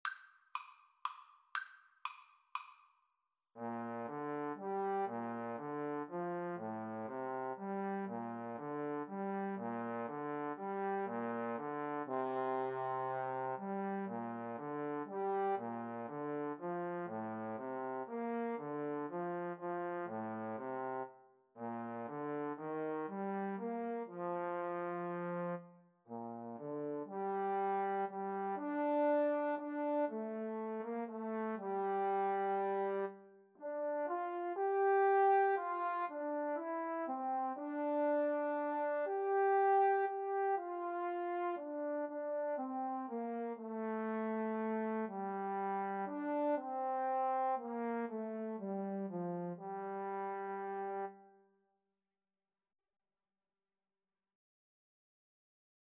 Trombone 1Trombone 2
Lento =120
3/4 (View more 3/4 Music)